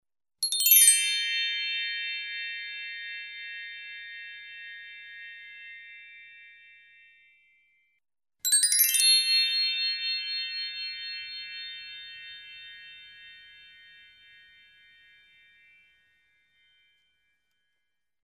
На этой странице собраны звуки хрусталя: легкие перезвоны, тонкие вибрации и магические мелодии.
Трель хрустальный